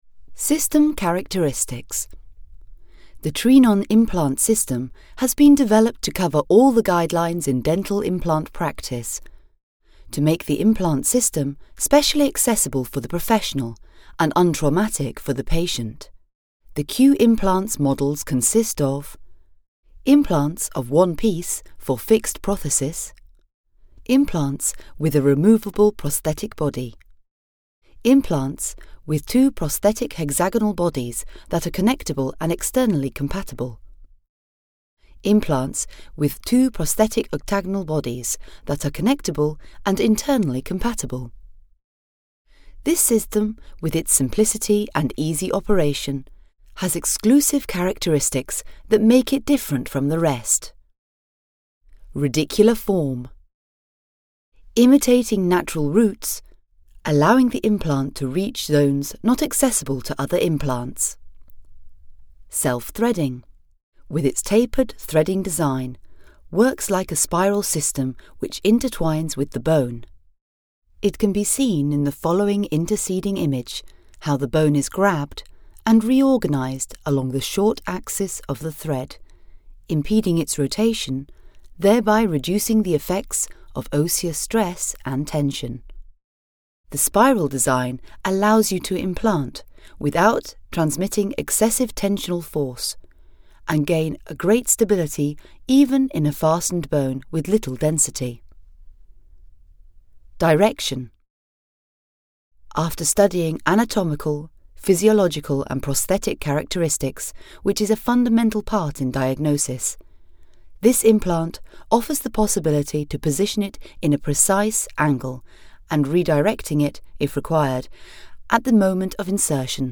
She offers a fast and efficient service with her professional home studio.
britisch
Sprechprobe: eLearning (Muttersprache):